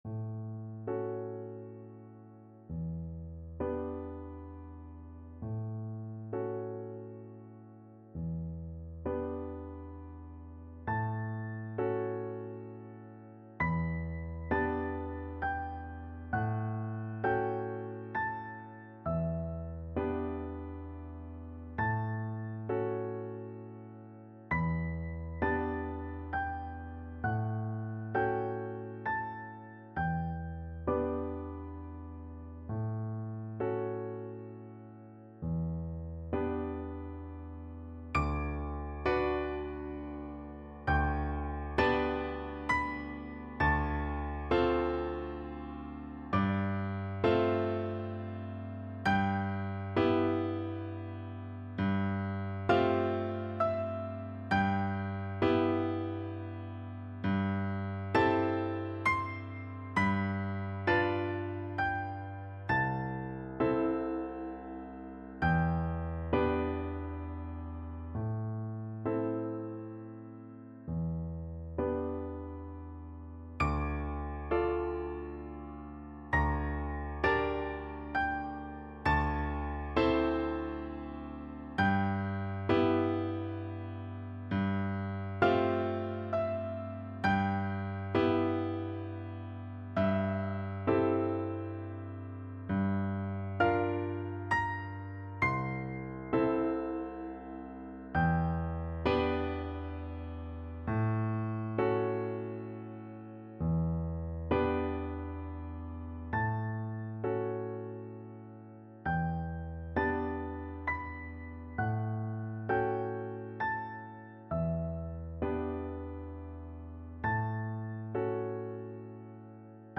Free Sheet music for Piano Four Hands (Piano Duet)
3/4 (View more 3/4 Music)
Lent et triste = 66
Classical (View more Classical Piano Duet Music)